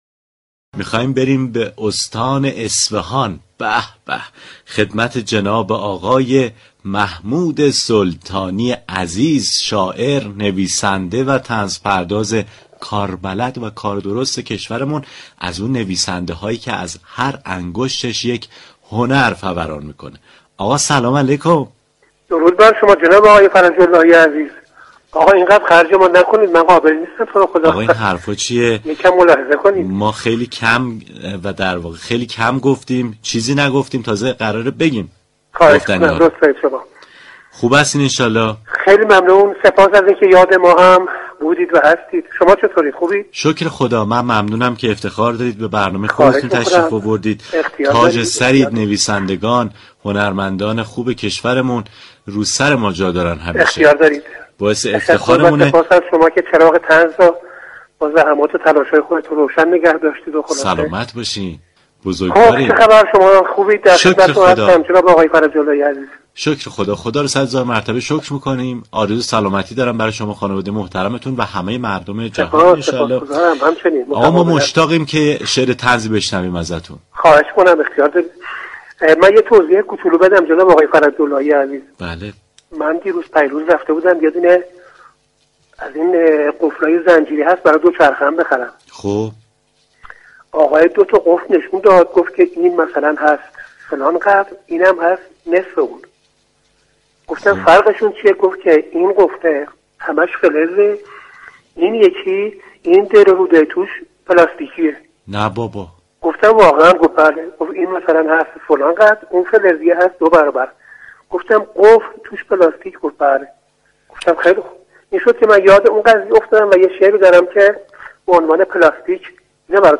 گفتگوی رادیو صبا